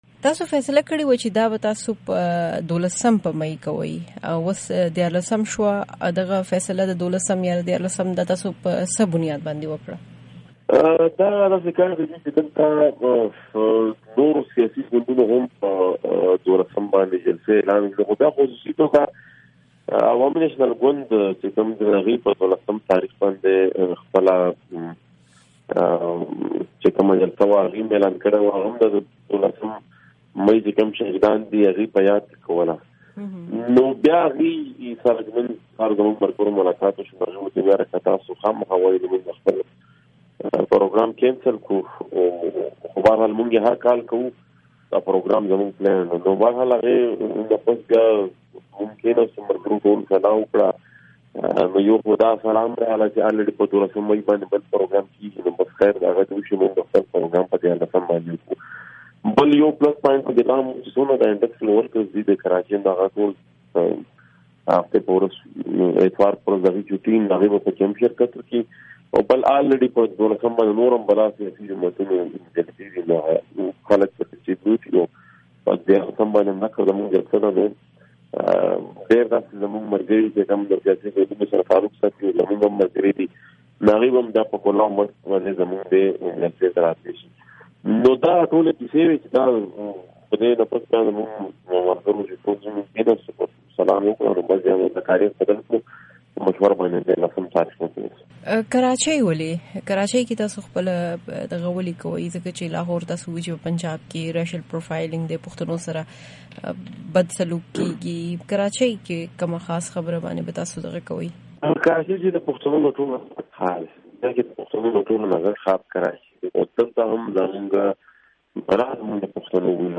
د کراچۍ د راتلونکي غونډې په اړه د محسن داوړ مرکه